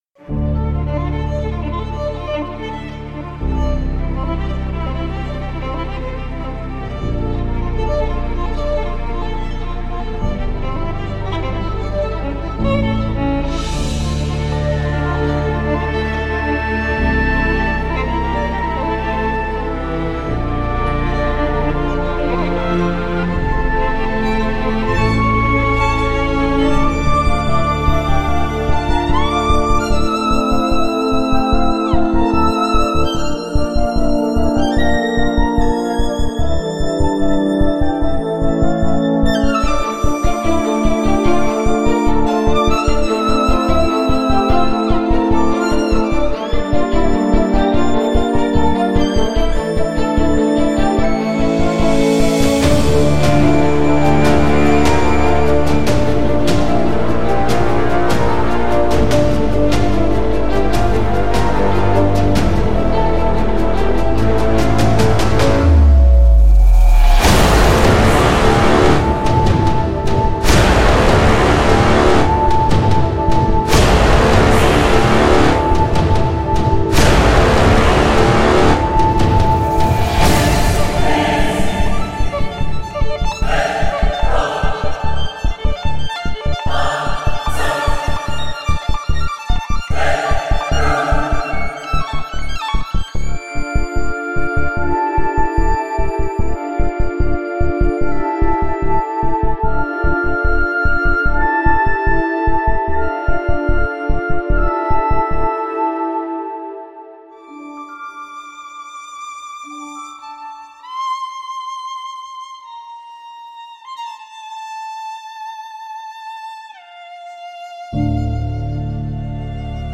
连奏、延音、断奏、短奏、颤音、颤音、和声……我们录制了您对小提琴独奏所期望的一切。
这个房间对我们来说是完美的空间，因为它为声音提供了足够的空间来发挥作用，而不会影响声音，也不会产生不必要的反射。